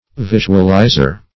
Visualizer \Vis"u*al*iz`er\, n.